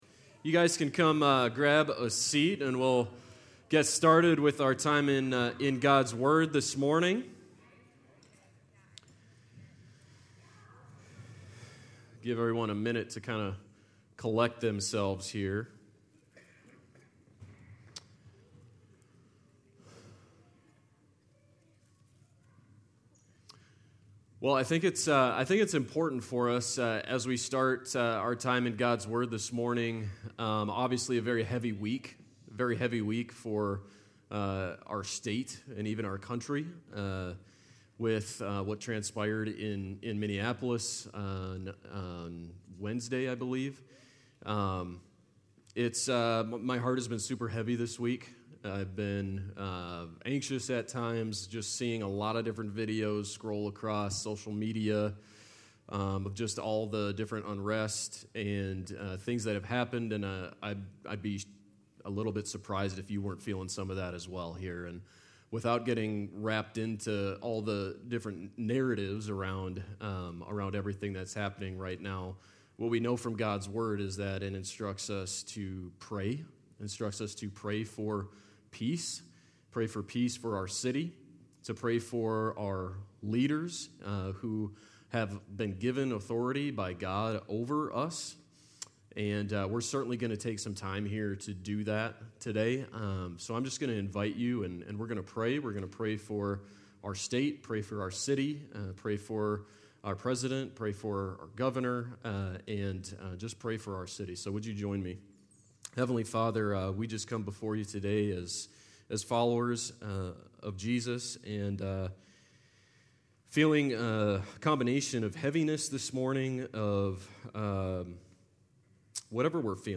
Whether it’s to call a friend you haven’t talked to in a while, or start up a conversation with a stranger, we know God uses us when we obey the God nudge. In this sermon, we look to Acts 8:26-40 to find three reasons to obey and trust the God nudge.